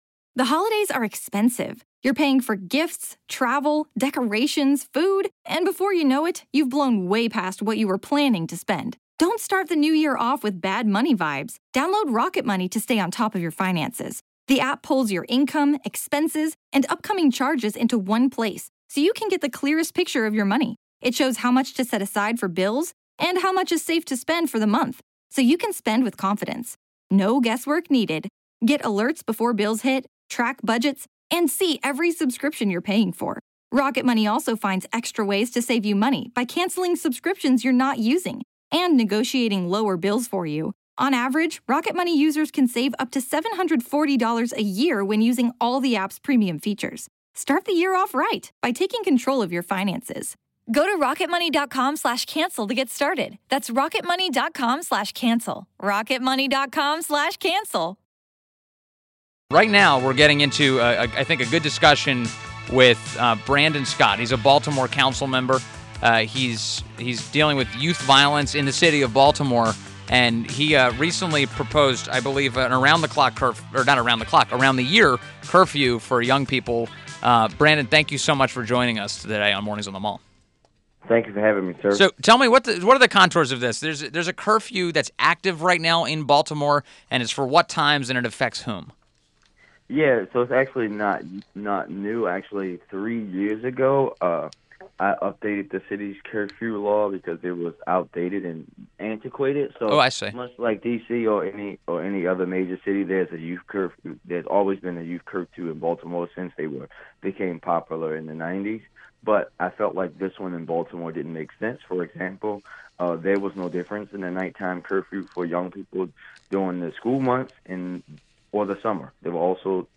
WMAL Interview - BRANDON SCOTT 05.31.17